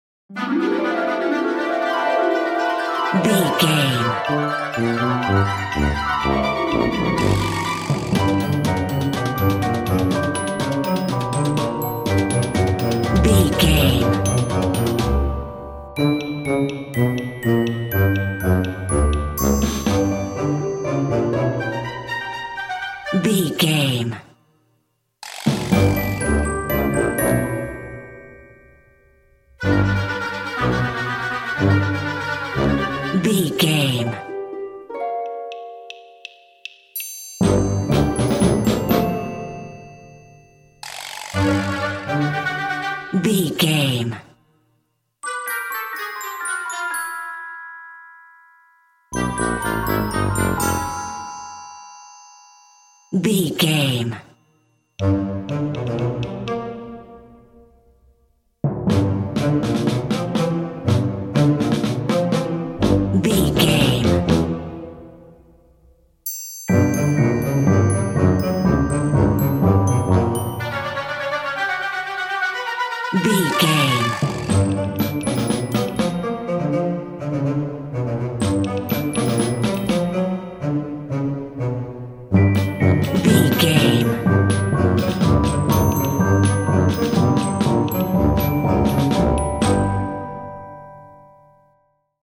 A fast/zany orchestral piece with hints of comical mischief.
Mixolydian
Fast
energetic
lively
playful
flute
oboe
strings
orchestra
harp